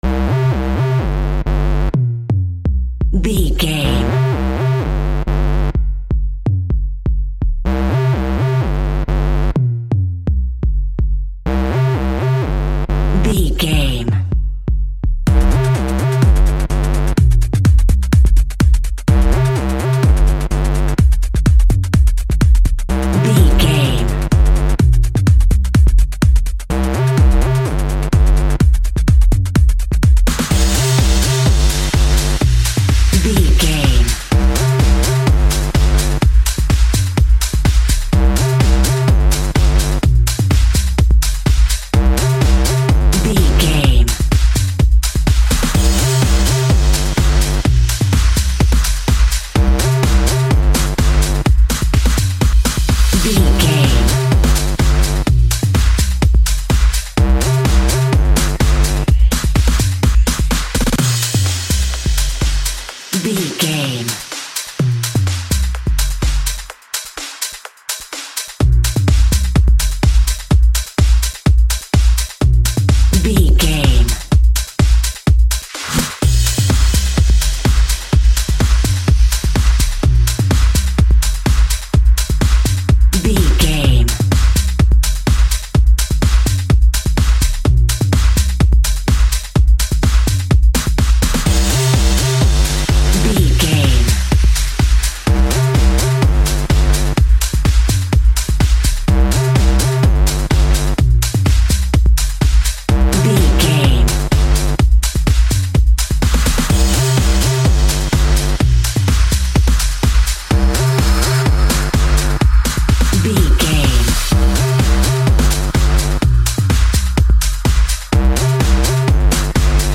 Club Rave Music Theme.
Aeolian/Minor
Fast
groovy
uplifting
driving
energetic
synthesiser
drum machine
house
techno
trance
synth bass
upbeat